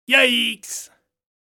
Funny Yaiks Sound Effect
A short, playful “yaiks” sound effect perfect for cartoons, comedic moments, animations, and humorous audio projects. This expressive vocal SFX adds character and surprise to any scene.
Funny-yaiks-sound-effect.mp3